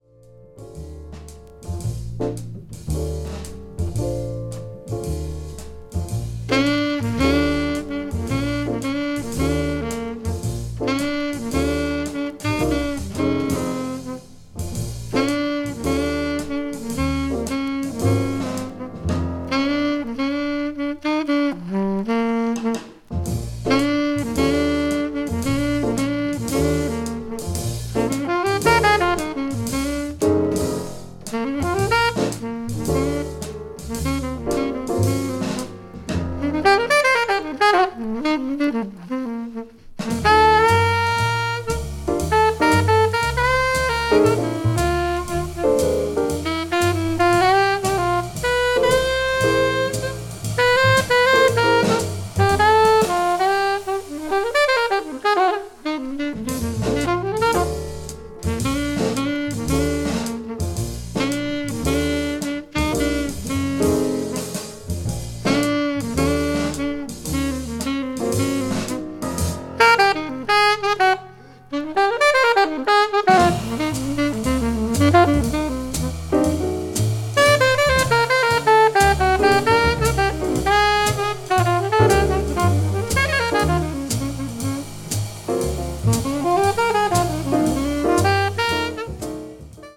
media : EX/EX(some slightly noises.)
hard bop   modern jazz